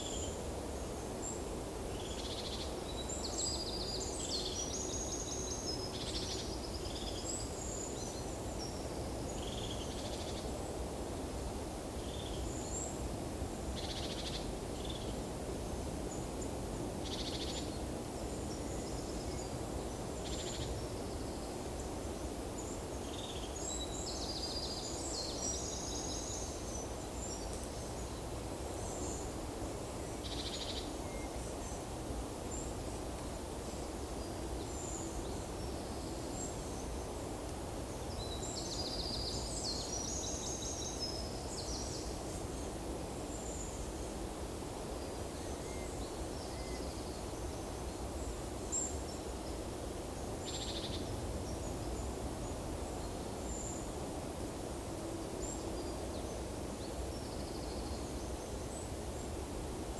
Lophophanes cristatus
Regulus regulus
Certhia familiaris
Parus major
Troglodytes troglodytes
Turdus iliacus
Pyrrhula pyrrhula
Poecile palustris